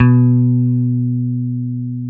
Index of /90_sSampleCDs/Roland LCDP02 Guitar and Bass/GTR_Dan Electro/BS _Dan-O Bass